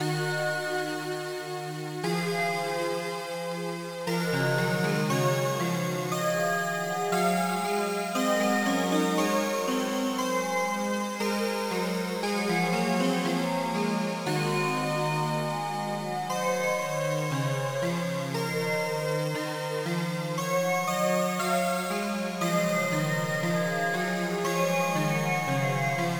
Synth Brightness + Strings